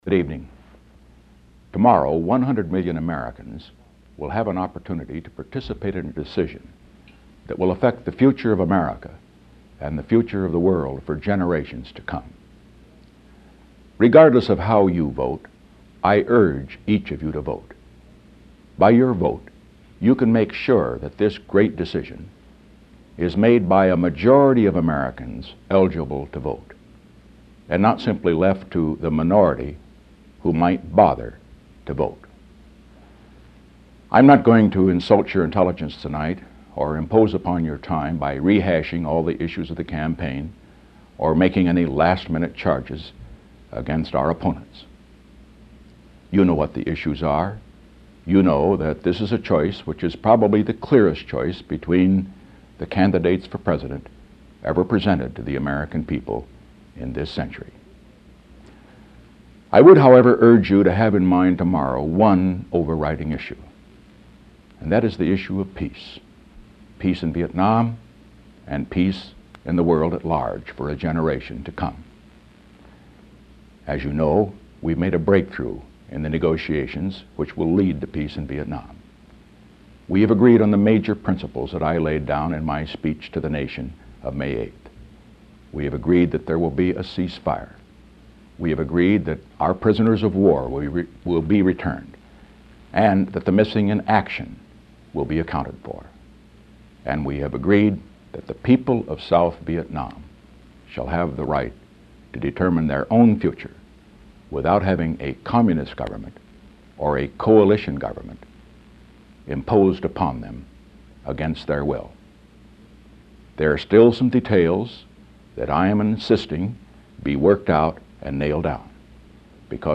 Presidential Speeches | Richard M. Nixon Presidency